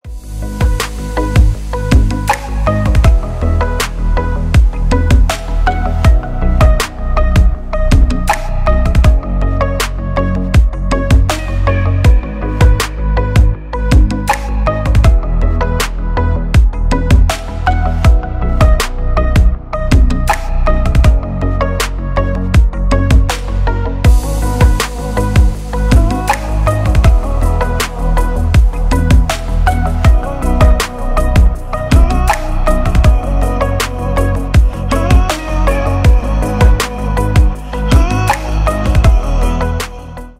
Поп Музыка
без слов